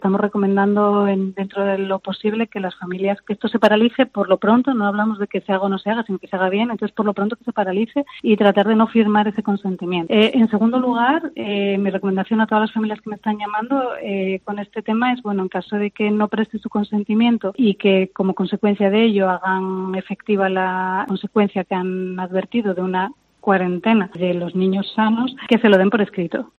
abogada y asesora en derecho educativo